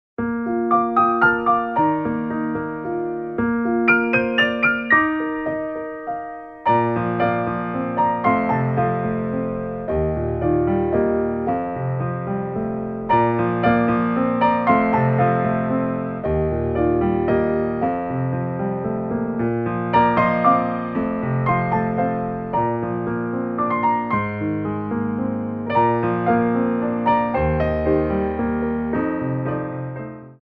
3/4 (16x8)